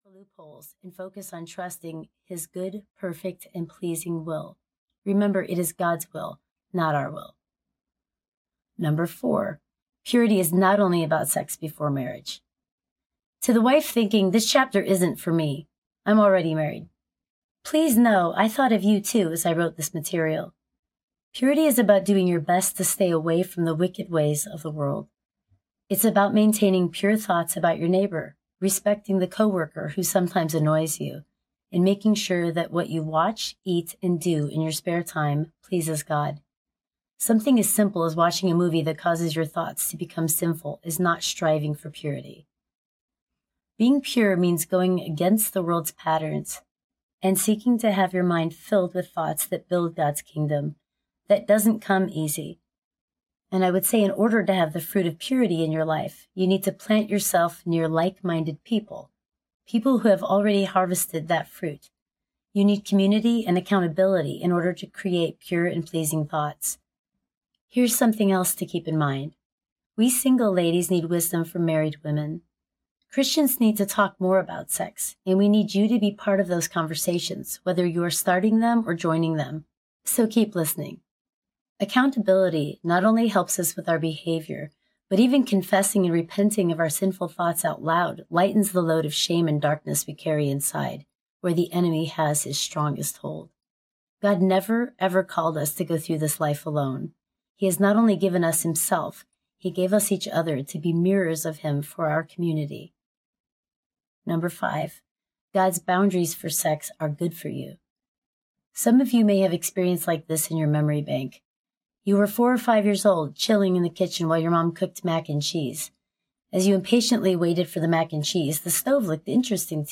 Am I Enough? Audiobook
Narrator